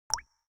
TW_Waterdrop.ogg